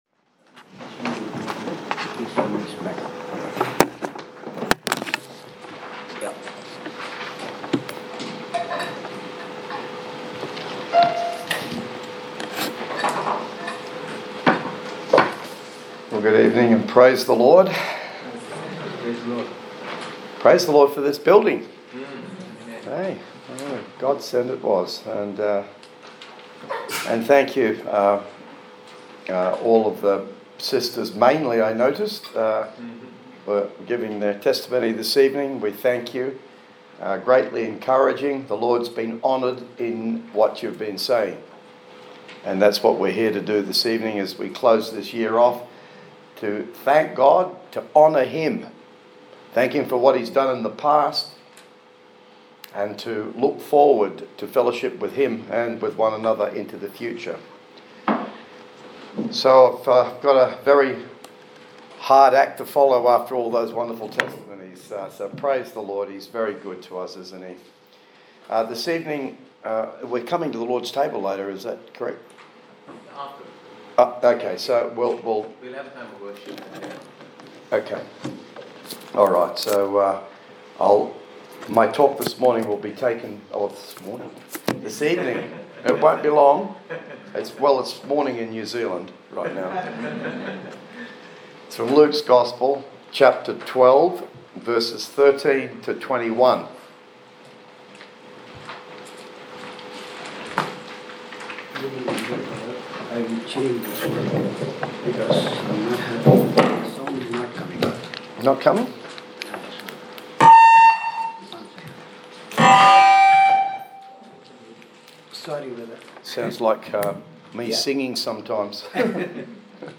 WATCH NIGHT SERVICE MESSAGE
Watch_Night_Service_2024.mp3